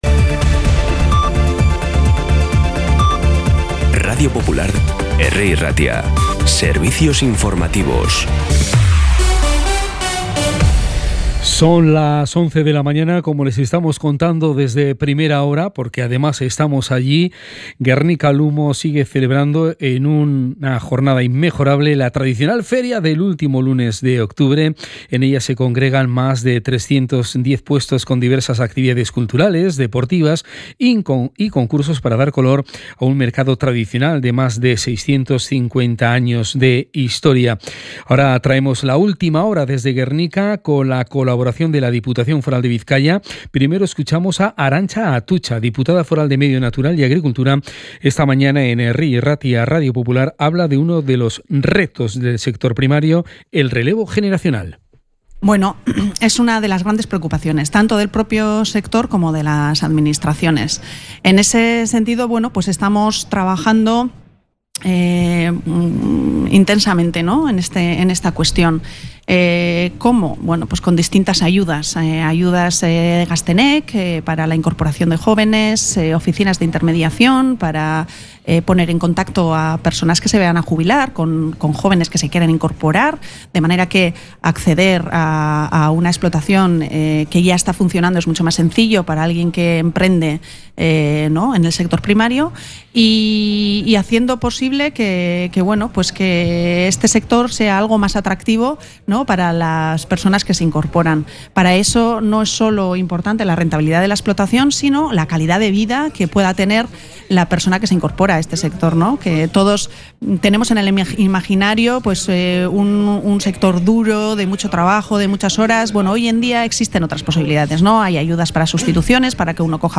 Las noticias de Bilbao y Bizkaia del 27 de octubre a las 11
Los titulares actualizados con las voces del día.